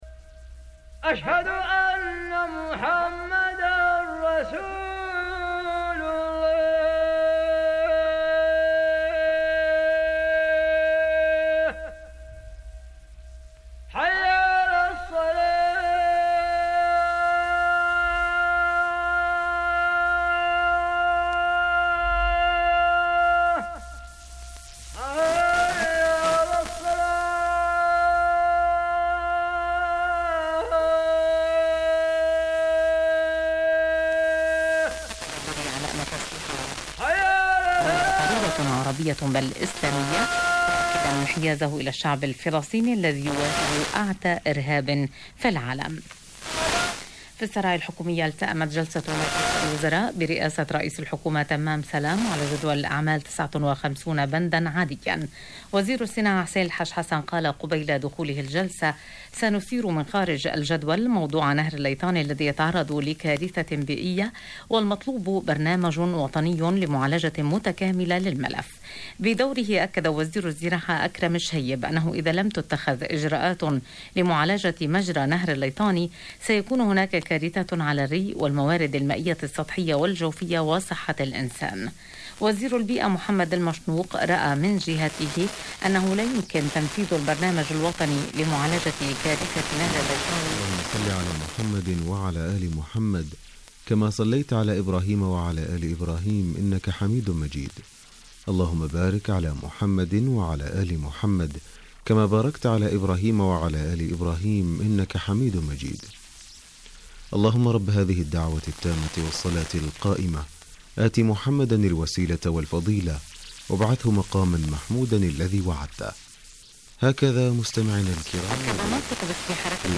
amikor a szír adás üti a szaúdit és fordítva - ima és férfi bemondó Radio Al-Quran Al-Kareem Szaudarábiából, a hírolvasó hölgy a a szír Sawt al-Sha'ab rádióból...